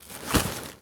foley_sports_bag_movements_01.wav